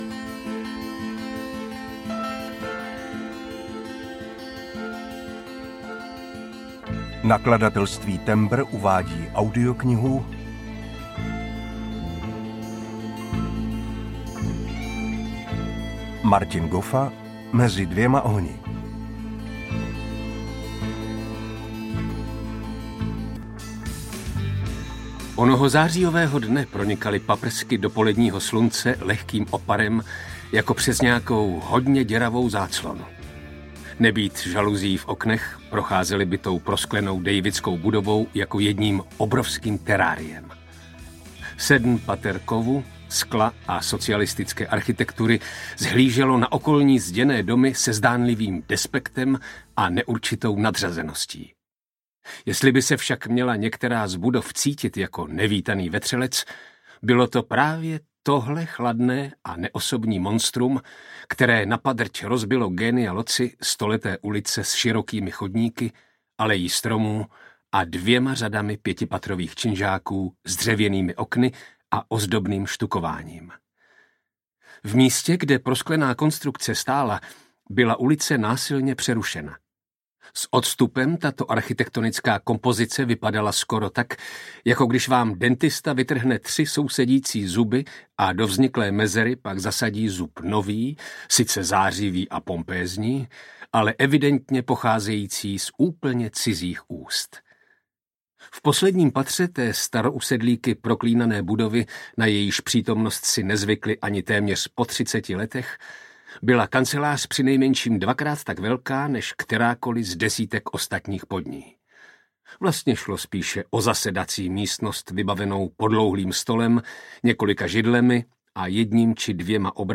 Mezi dvěma ohni audiokniha
Ukázka z knihy